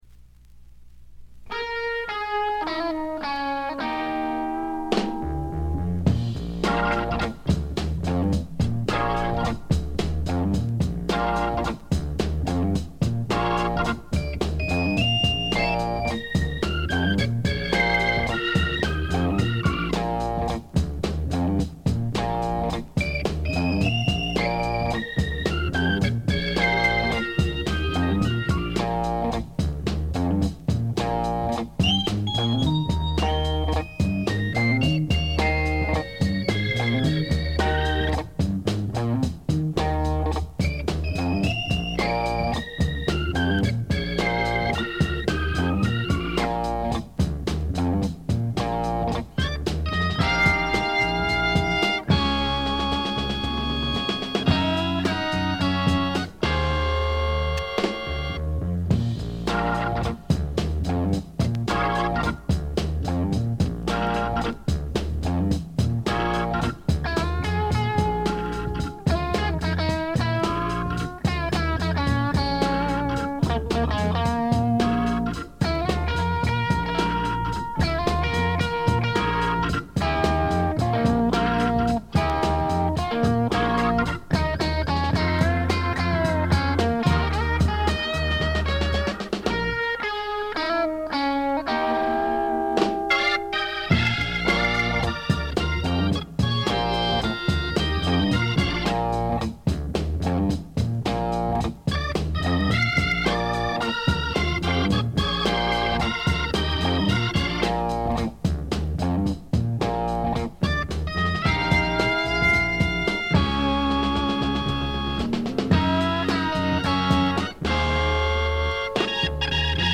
全体にチリプチ、プツ音多め大きめ。
試聴曲は現品からの取り込み音源です。